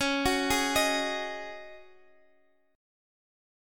Dbsus2sus4 Chord
Listen to Dbsus2sus4 strummed